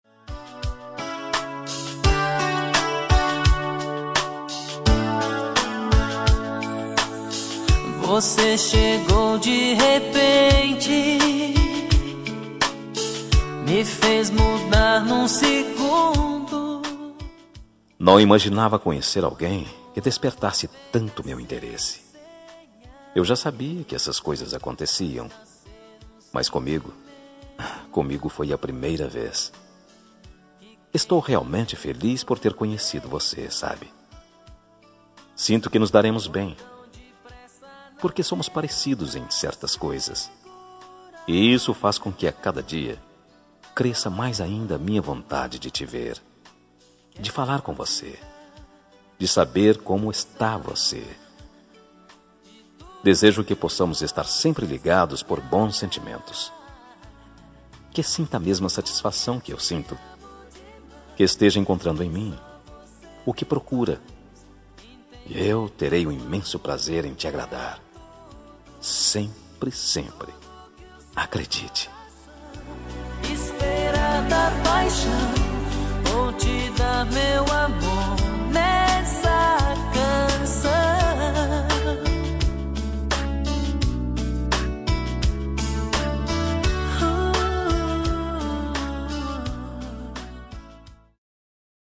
Telemensagem Momentos Especiais – Voz Masculina – Cód: 201884 – Adorei te Conhecer – Linda